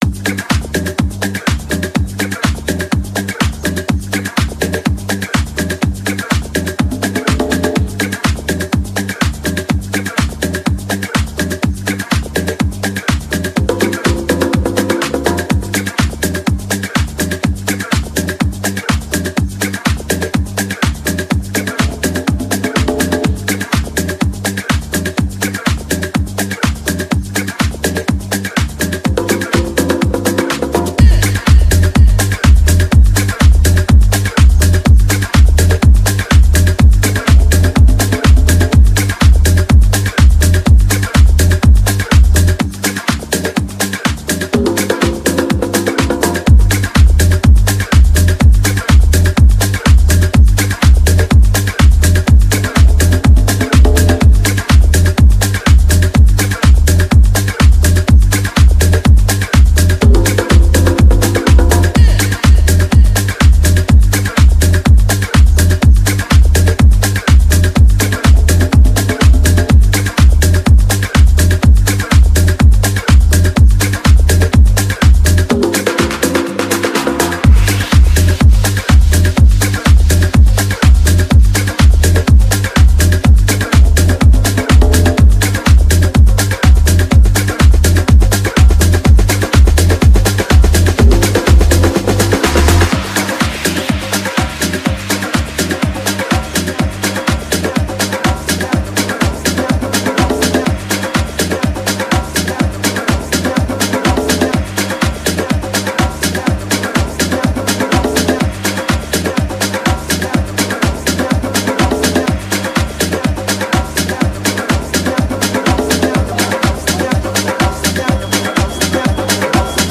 TOP MASHUPS